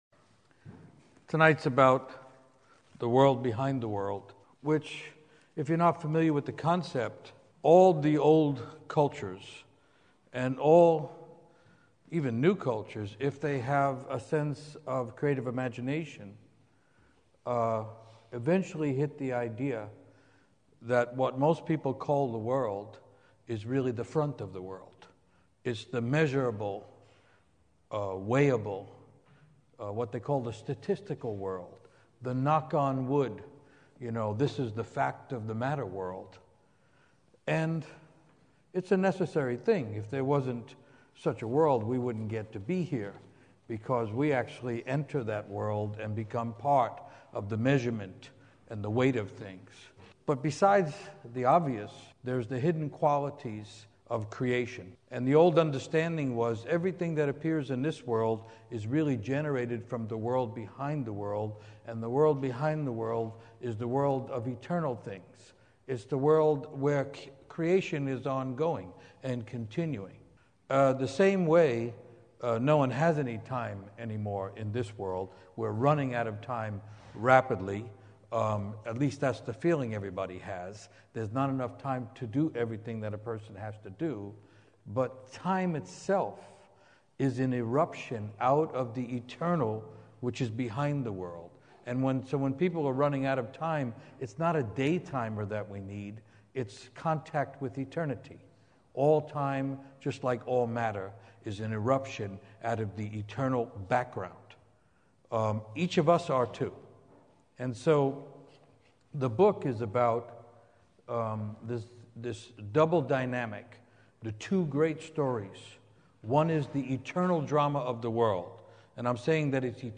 Writer Michael Meade speaking at the Old Church in Portland, Oregon, on 4/24/08.